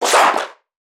NPC_Creatures_Vocalisations_Infected [107].wav